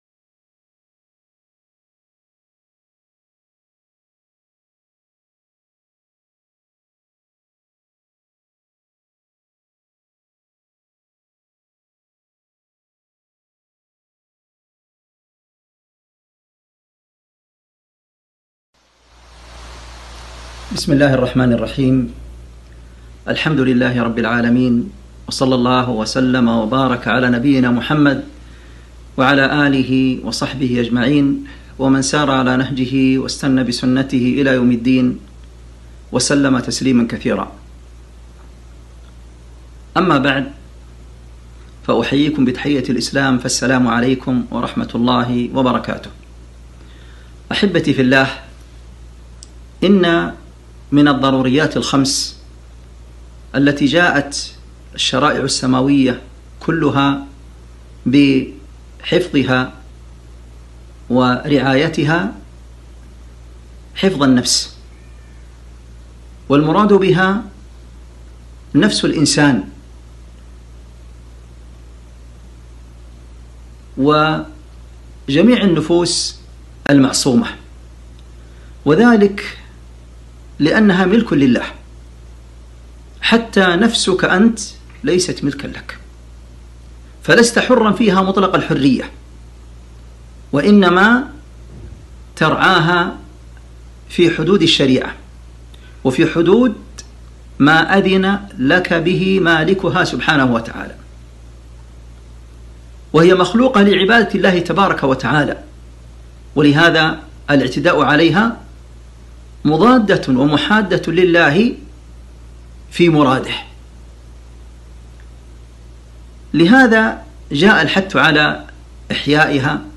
محاضرة - أسباب وقوع البلاء وطرق دفعه في ضوء الكتاب والسنة عبر البث المباشر 1441هــ